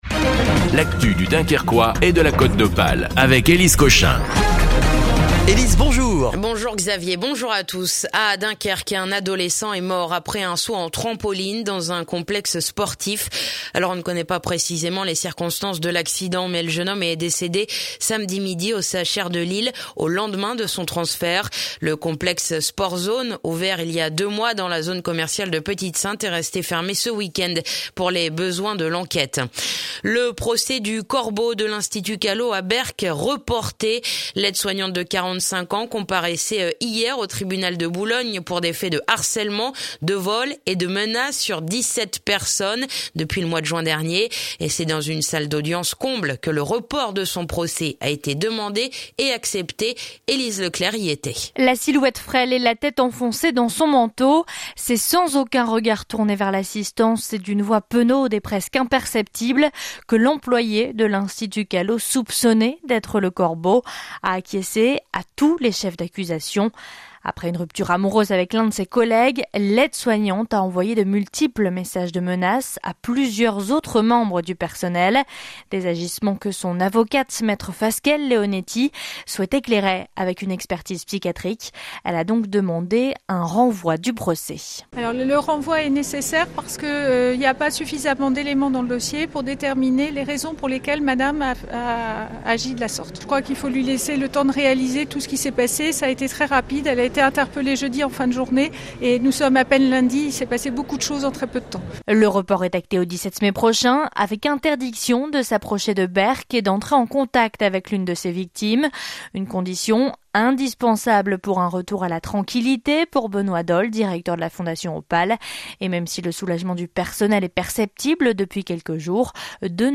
le journal du mardi 20 février dans le dunkerquois